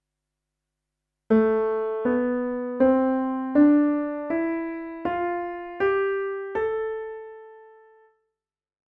A minor scale
Ex-3-A-minor-scale.mp3